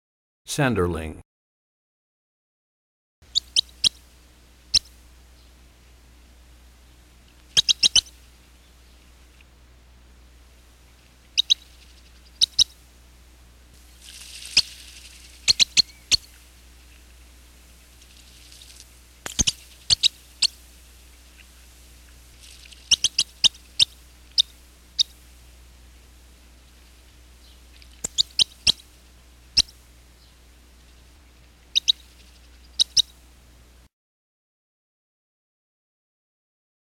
78 Sanderling.mp3